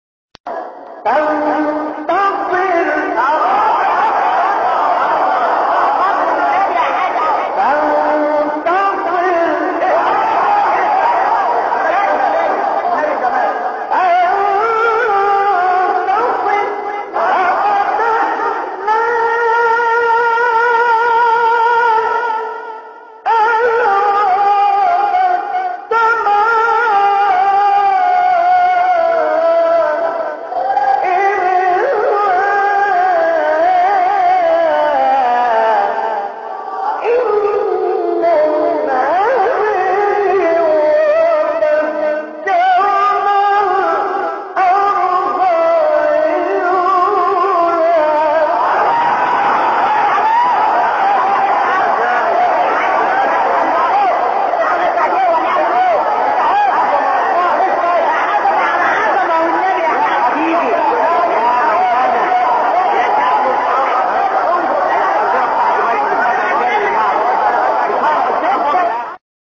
گروه شبکه اجتماعی: فرازهای صوتی از تلاوت قاریان به‌نام مصری را می‌شنوید.